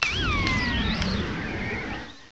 sovereignx/sound/direct_sound_samples/cries/bramblin.aif at fca19a03f1dfba888c0be4db3ebd466a4d6a6ef4